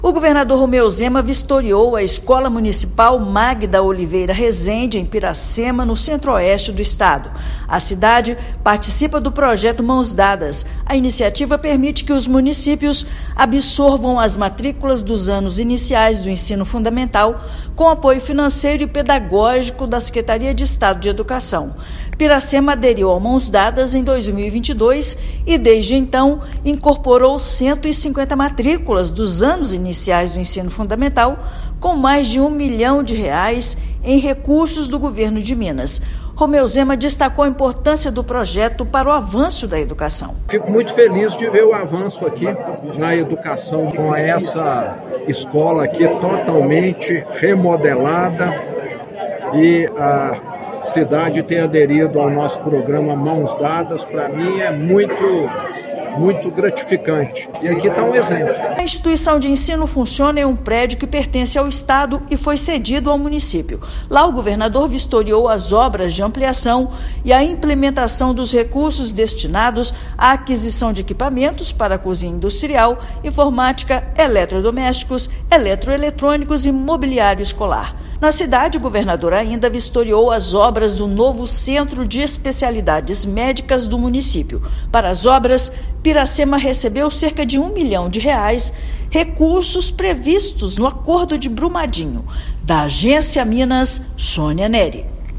Parceria com a escola gerou investimentos de cerca de R$ 1 milhão em melhorias para transformar a educação na cidade; Recurso do Centro de Especialidades Médicas veio através do Acordo de Brumadinho. Ouça matéria de rádio.